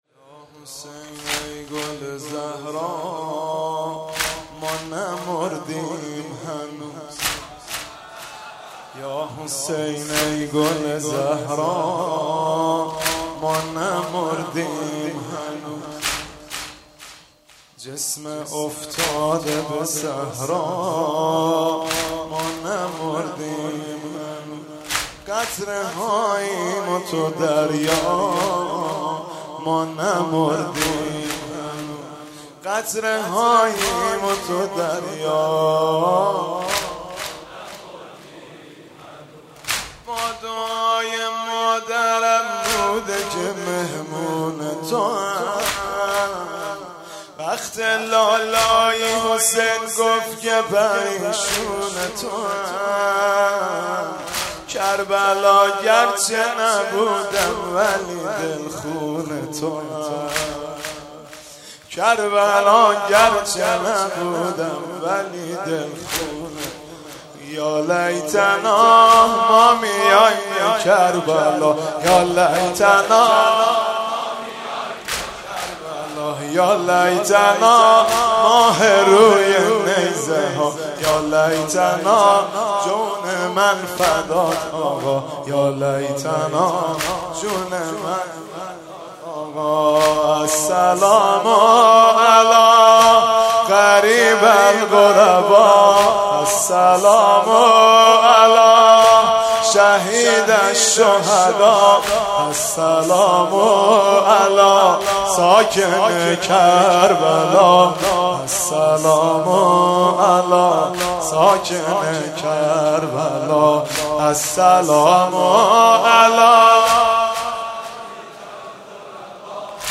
شب عاشورا محرم95 /هیئت ثارالله زنجان